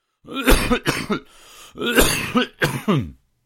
SICK sounds » 00553 coughing man 2
描述：male cough single one rec by AKG D80
标签： ill oneshot sick caughing man caugh
声道立体声